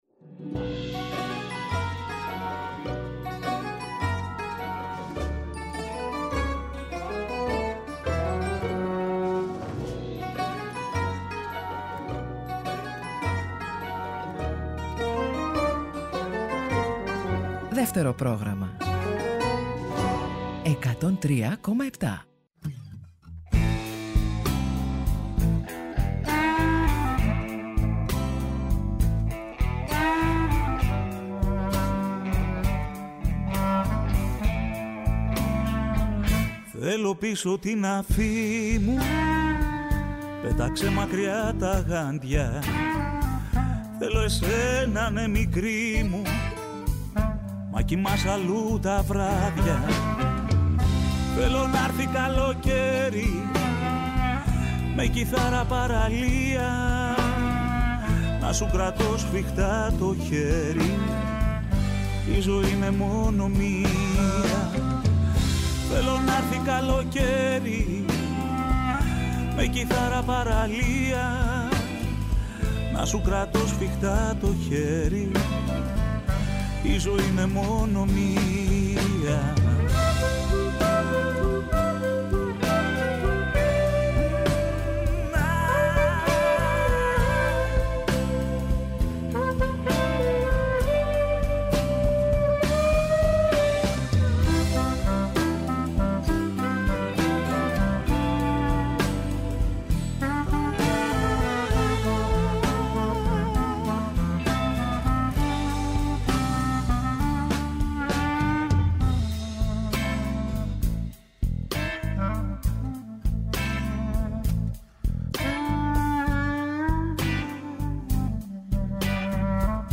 καλεσμένος τηλεφωνικά